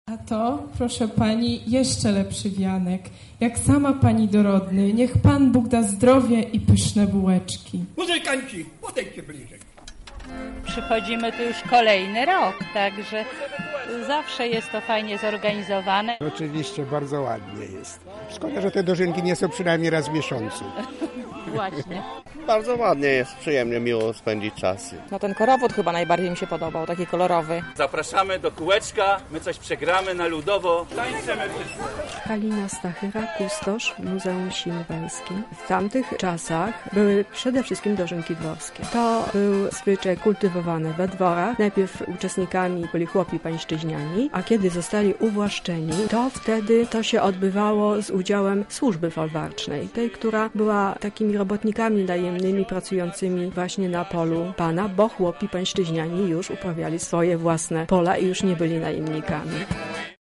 W lubelskim skansenie odbyły się dożynki dworskie
Na miejscu była nasza reporterka: